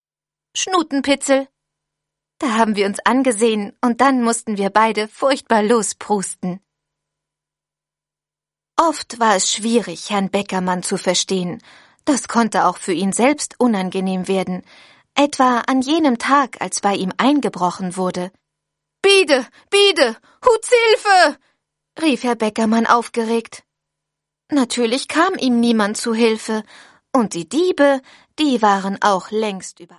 Ravensburger Der Wechstabenverbuchsler + Der Wechstabenverbuchsler im Zoo ✔ tiptoi® Hörbuch ab 4 Jahren ✔ Jetzt online herunterladen!
Hoerprobe-Der_Wechstabenverbuchsler.mp3